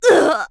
Laudia-Vox_Damage_kr_02.wav